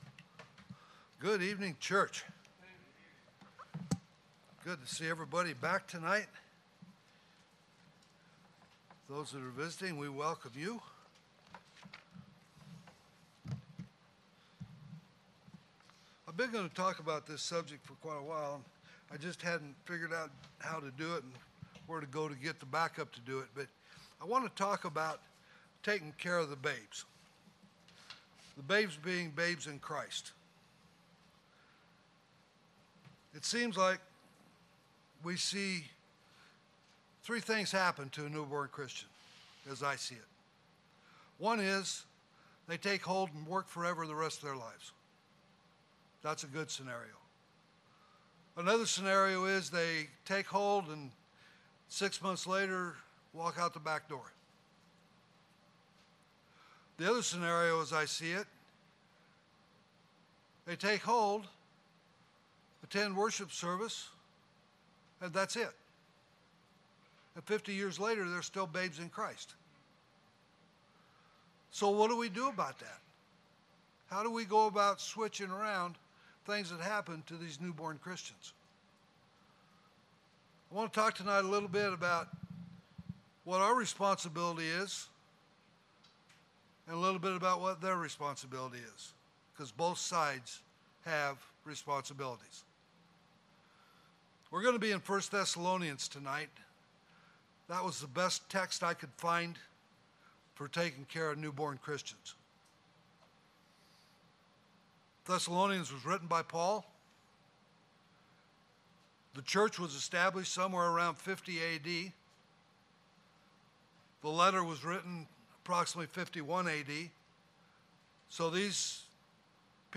2025 (PM Worship) “Takin’ Care Of The Babes”
Sermons